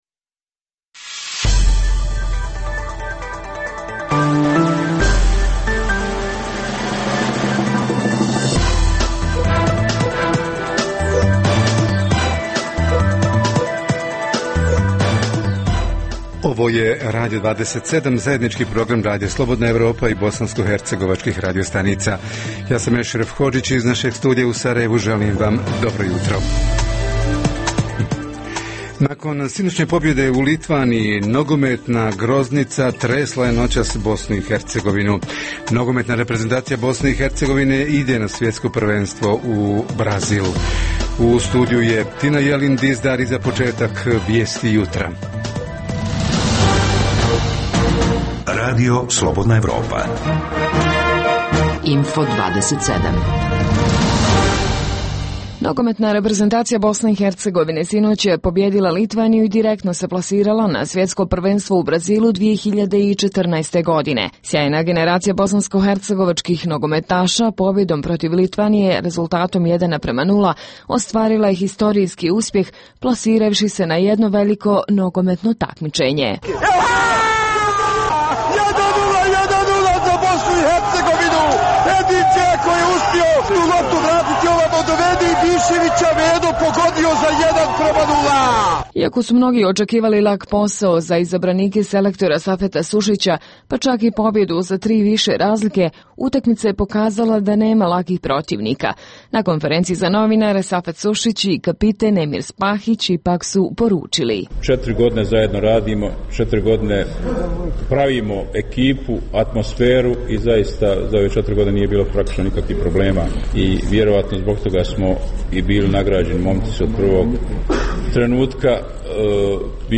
Radio 27 jutros donosi: - Info-plus i susret „uživo“ sa Sarajevom, Banjom Lukom, Mostarom i Brčko-distriktom BiH: - kako su Bosanci i Hercegovili pratili i kako su reagirali na ishod sinoćnje utakmice nogometnih reprezentacija BiH i Litvanije?